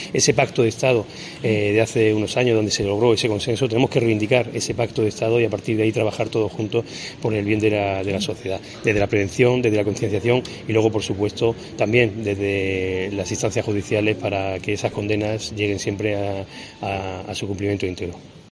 Intervención del alcalde de Jaén Agustín González